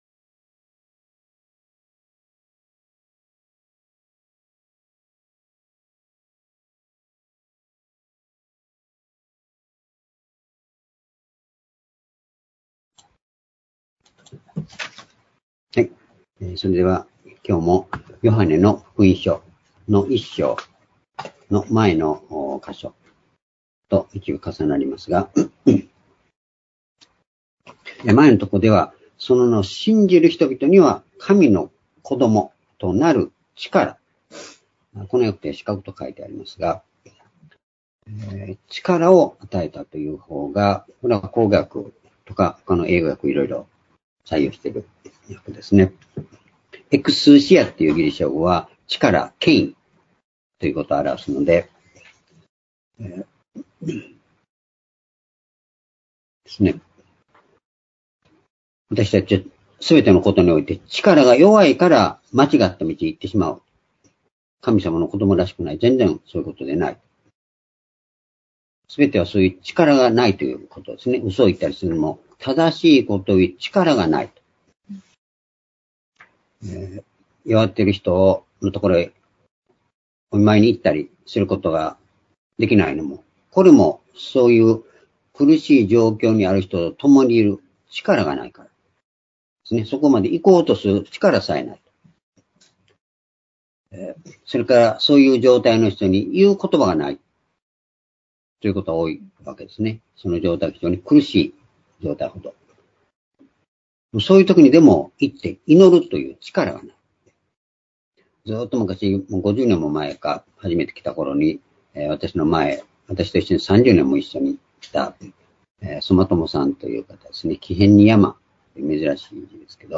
「神のご意志と真理（真実）、恵み」-ヨハネ福音書 １章13節～14節-2021年10月10日（主日礼拝）
主日礼拝日時 2021年10月10日（主日礼拝） 聖書講話箇所 「神のご意志と真理（真実）、恵み」 ヨハネ福音書 １章13節～14節 ※視聴できない場合は をクリックしてください。